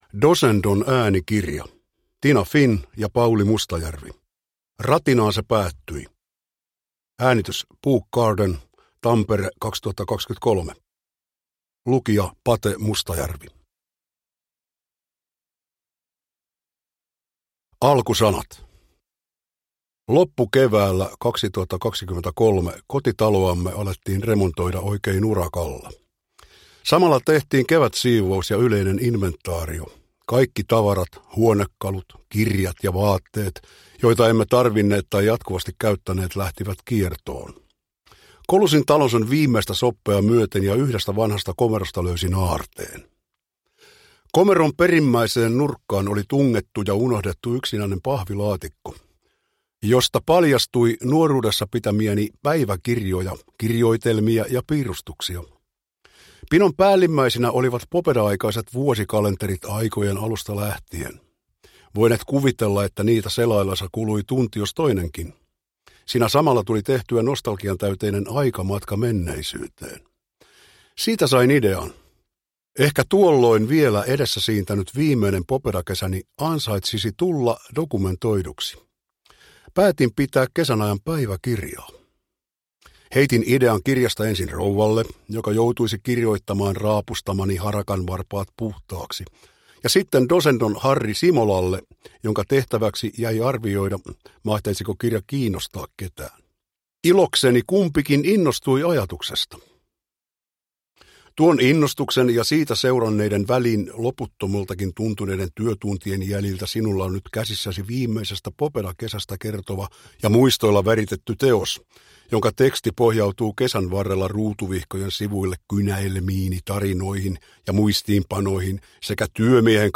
Uppläsare: Pauli Mustajärvi